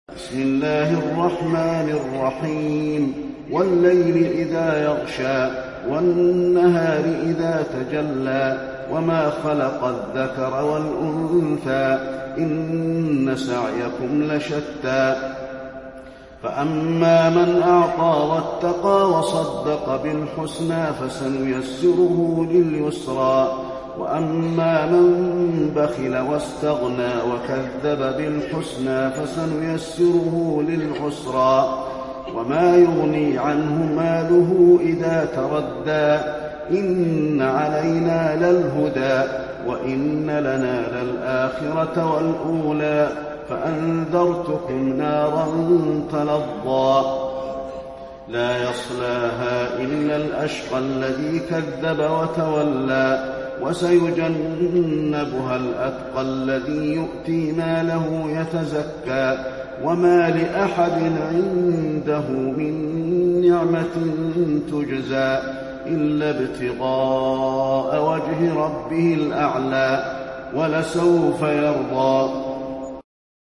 المكان: المسجد النبوي الليل